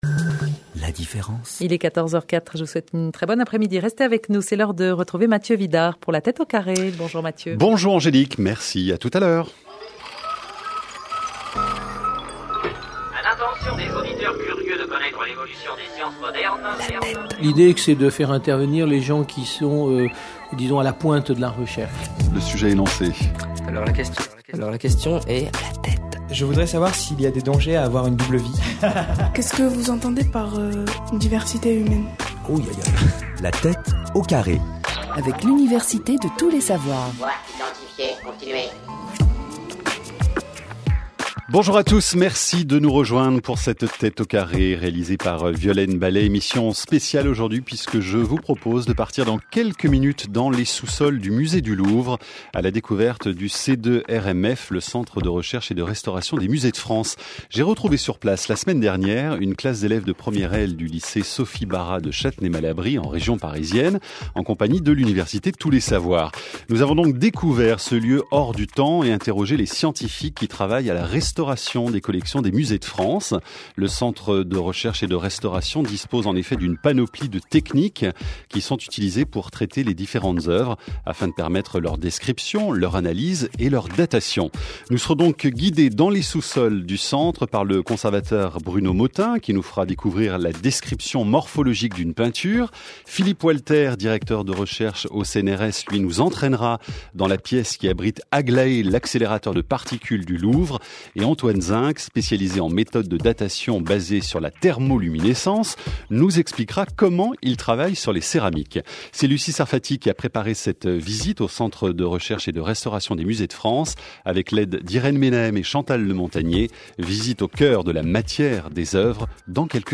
Utls au Lycée en partenariat avec la Tête au carré (émission en audio uniquement) Dans les sous-sols du musée du Louvre à la découverte du C2RMF, Centre de recherche et de restauration des Musées de France.